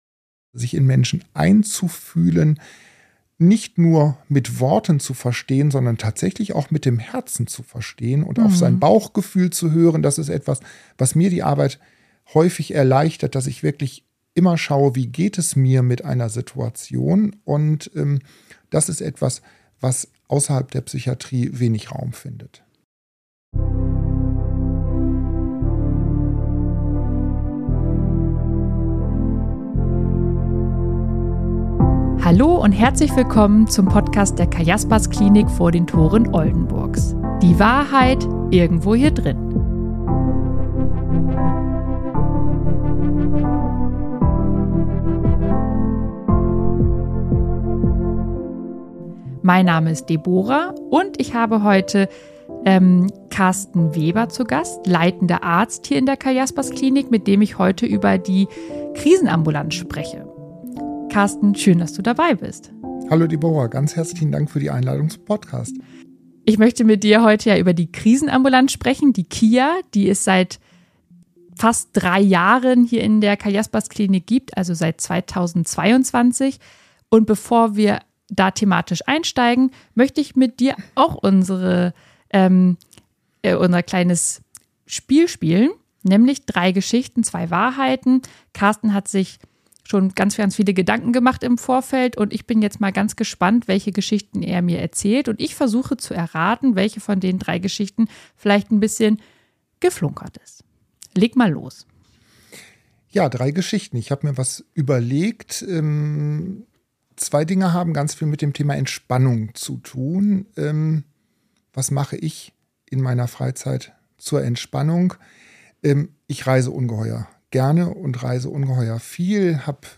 #28 KRISEN Experten-Talk ~ Die Wahrheit Irgendwo Hier Drinnen Podcast